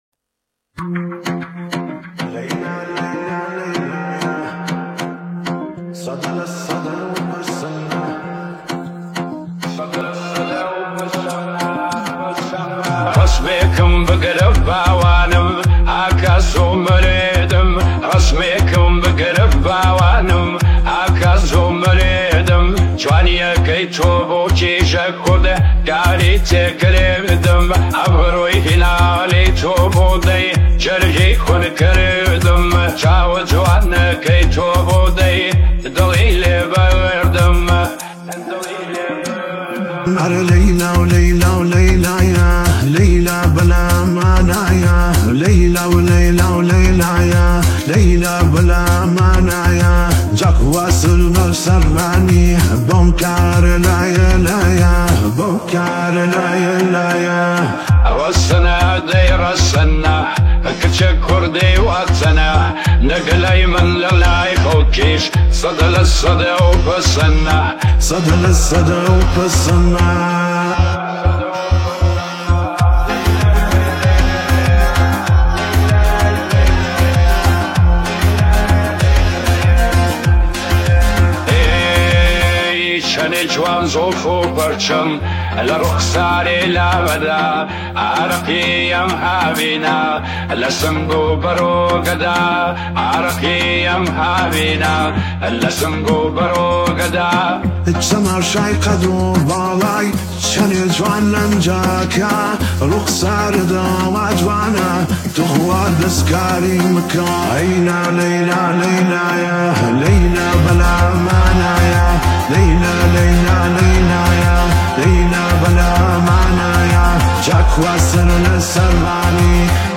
آهنگ کوردی